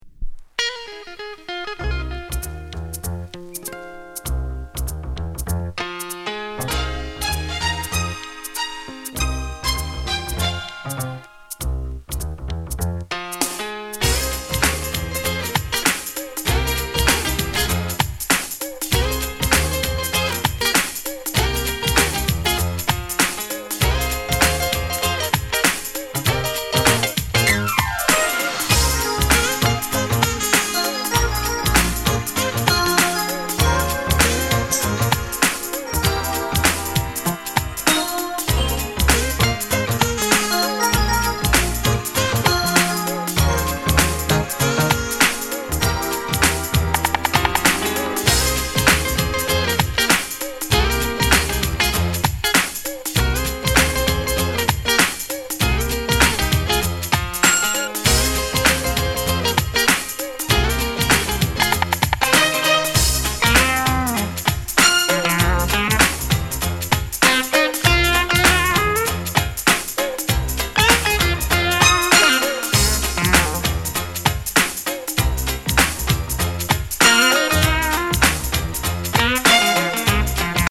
Genre: Funk